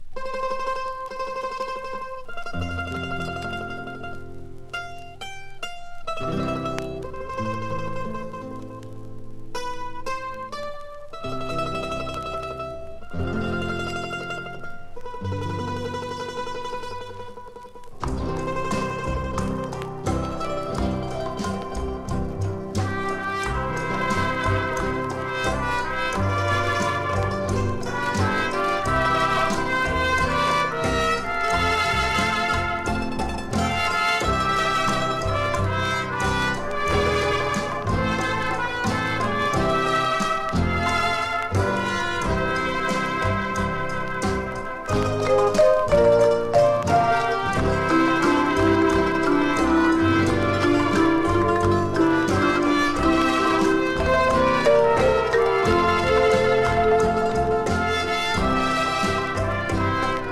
ポップなマリンバ・イージーリスニング！つま弾くラテンギターにパーカッションが印象的なおおらかBGM